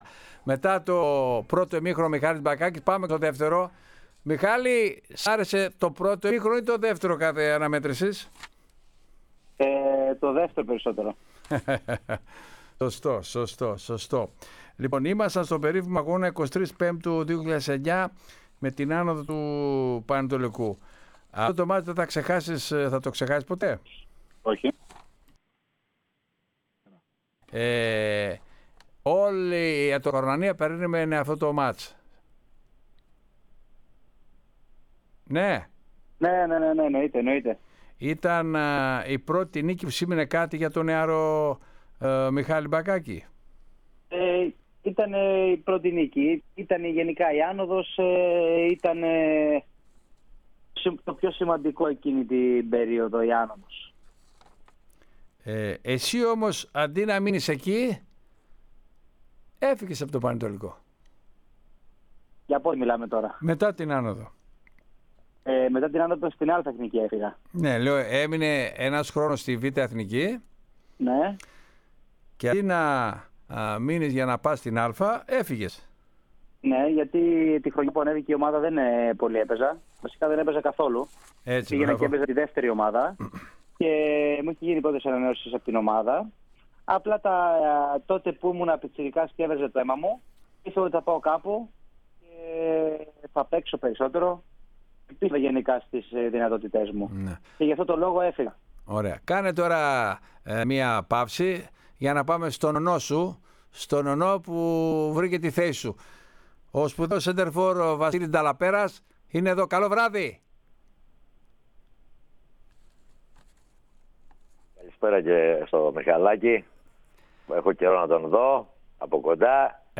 Ο Μιχάλης Μπακάκης, σε μια ειλικρινή και συναισθηματικά φορτισμένη συνέντευξη στην «ΕΡΑΣΠΟΡ», εξέφρασε τη βαθιά απογοήτευσή του για τον τρόπο με τον οποίο ολοκληρώθηκε η συνεργασία του με τον Παναιτωλικό.